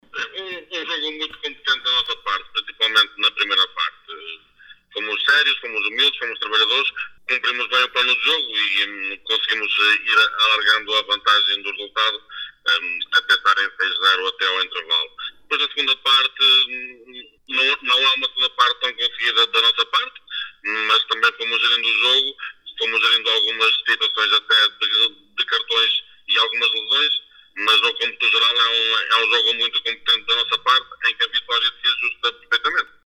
GDM-versus-Pedras-Salgadas-1.mp3